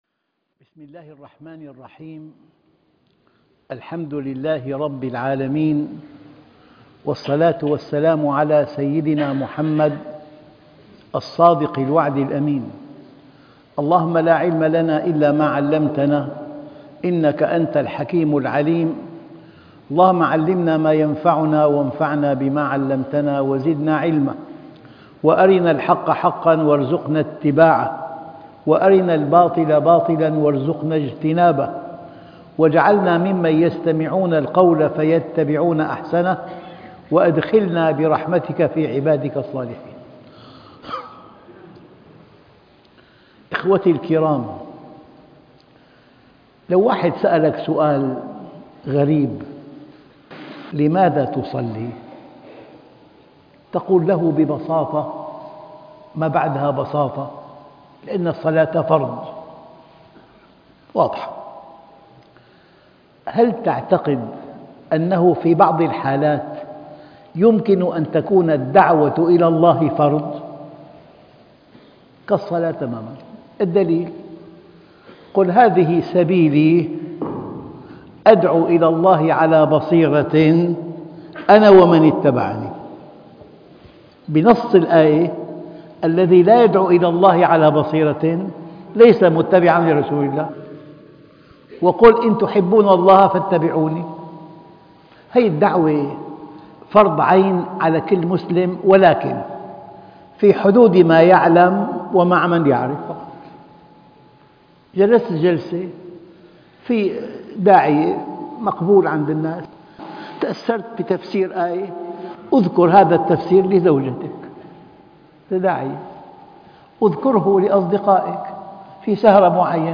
طلب العلم - الجزائر - محاضرة 23 - الشيخ محمد راتب النابلسي